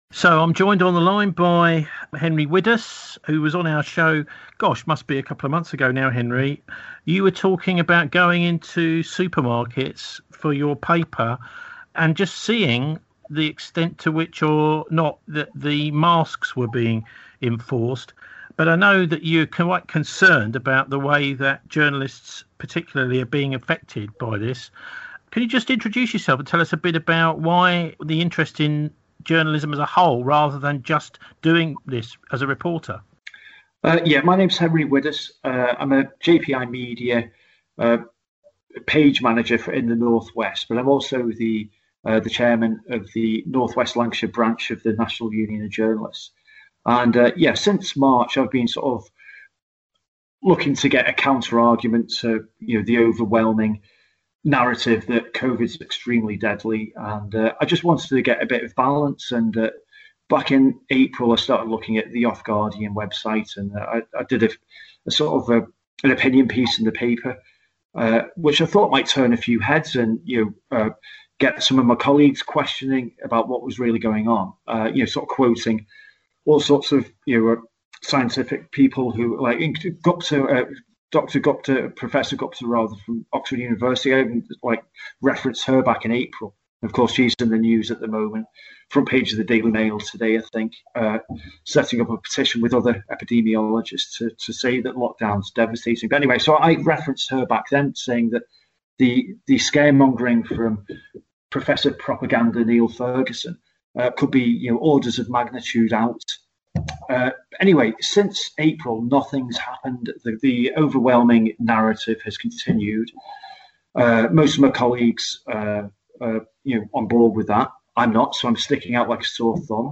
Radio4All download pages BCfm audio file – [right click to download] Radio4All audio file – [right click to download] Complete 120 minute show – [right click to download] Full anti-Covid-19 censorship journalists interview (HATA) [right click to download]